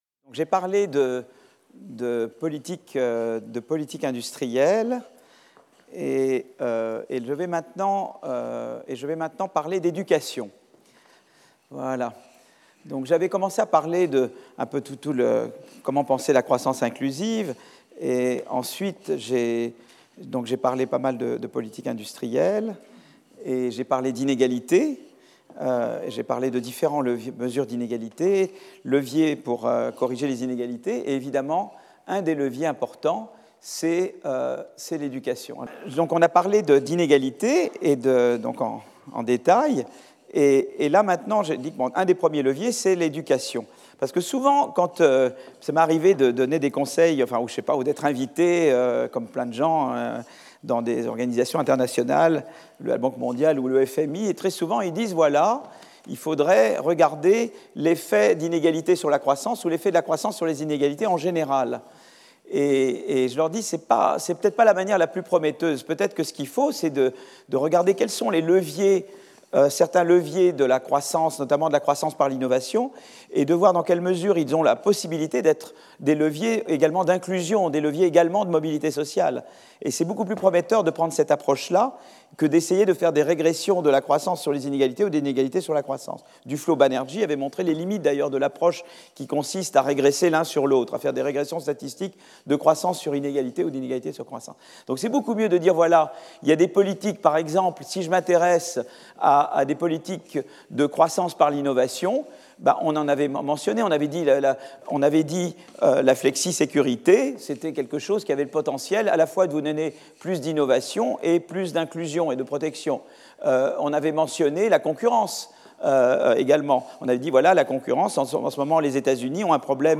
Philippe Aghion Professeur du Collège de France